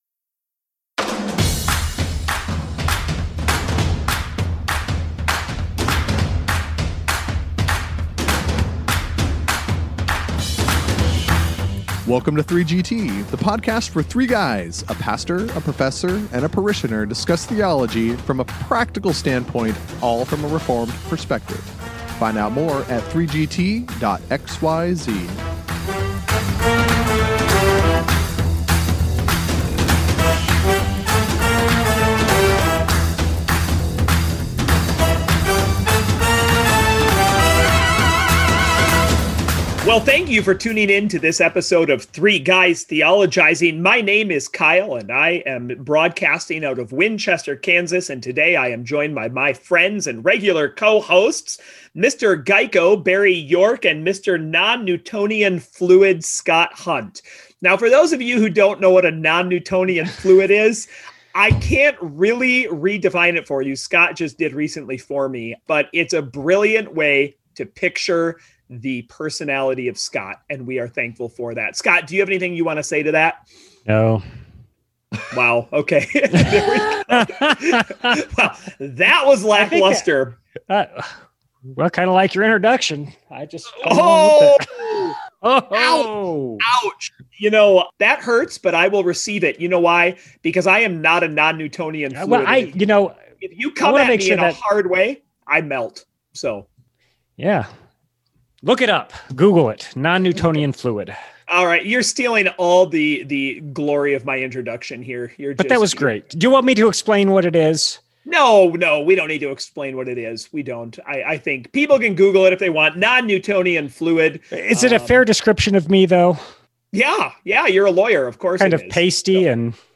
To answer that question, hit play, hit pause to look up non-Newtonian, then hit play again to join the discussion on this episode of Three Guys Theologizing!